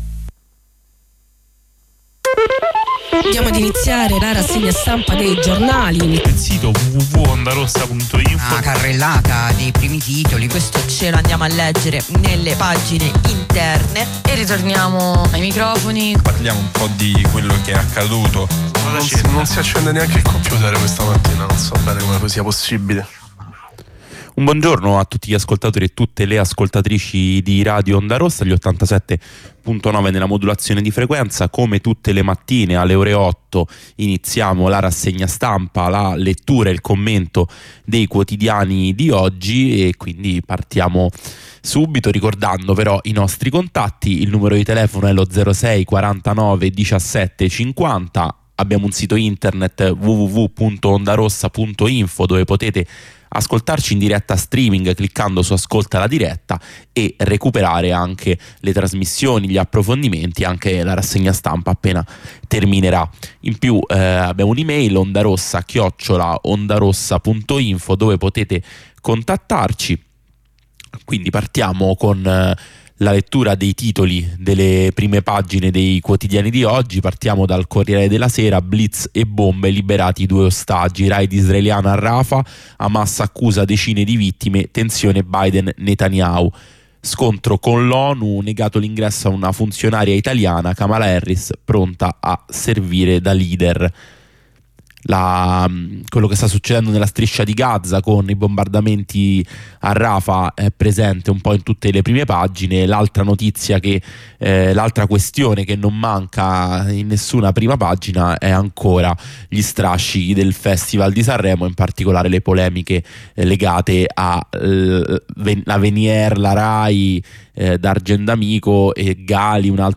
Lettura e commento dei quotidiani, tutte le mattine alle ore 8.